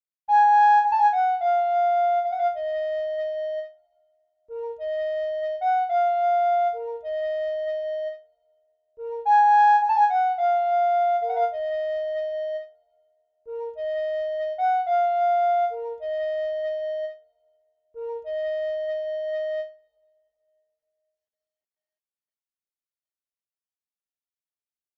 • Possibility to activate an ensemble effect.
• 69 stereo samples / 48kHz, 24-bits.